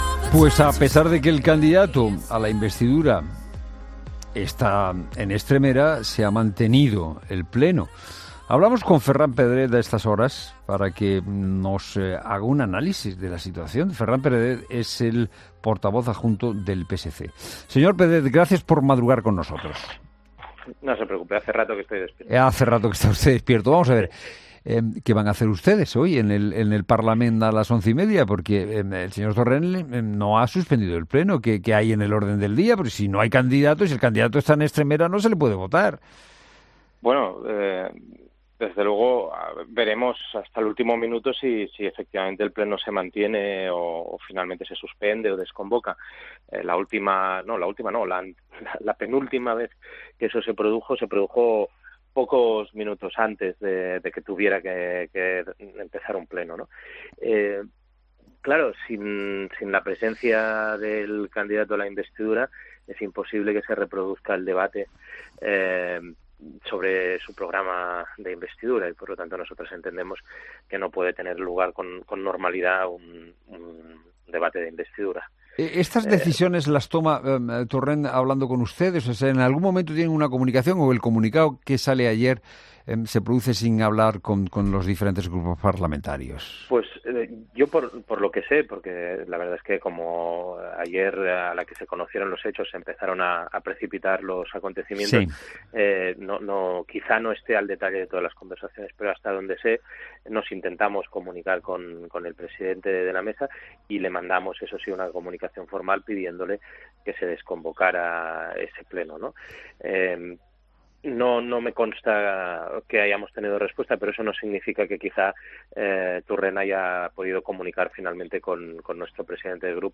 El diputado socialista en Cataluña, Ferran Pedret, habla de los últimos acontecimientos de Cataluña